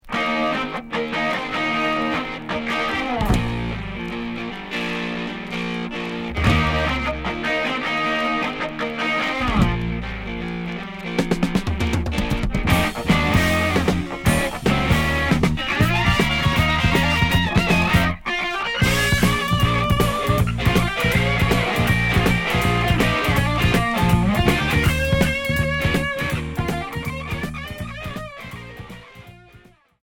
Heavy rock boogie Premier 45t retour à l'accueil